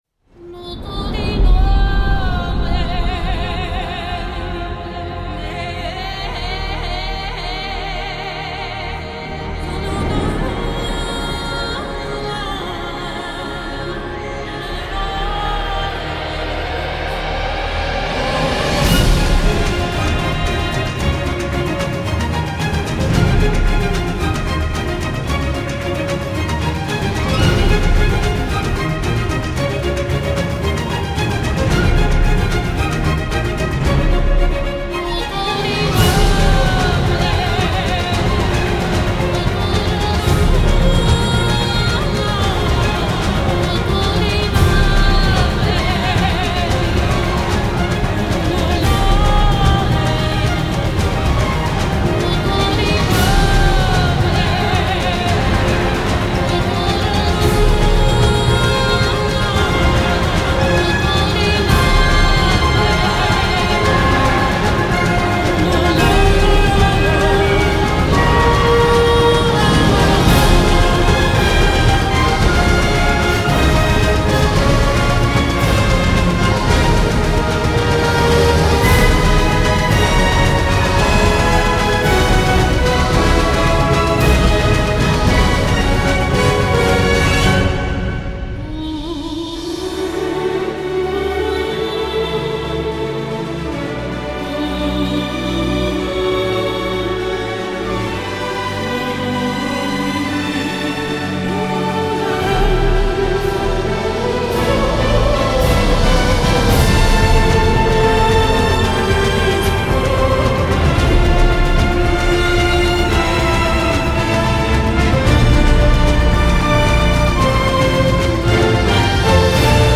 チェロ